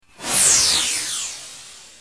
SFX清脆字幕咻影视配乐音效下载
SFX音效